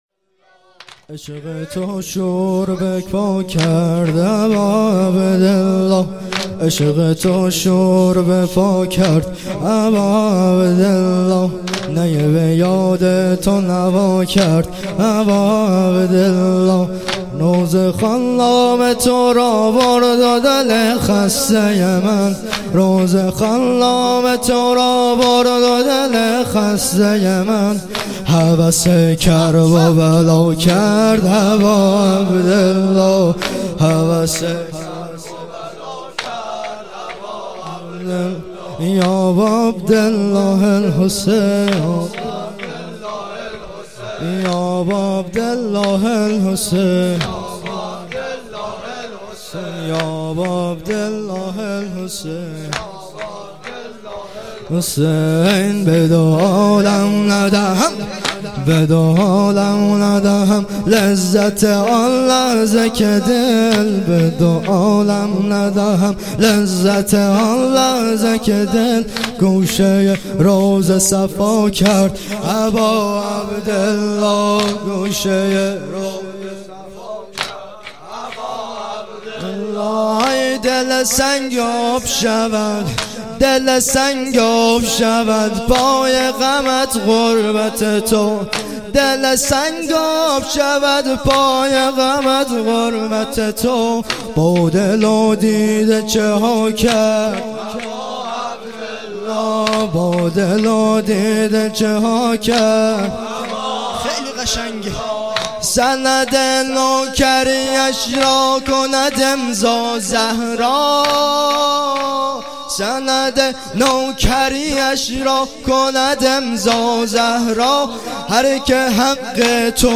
97.2.20 مراسم هفتگی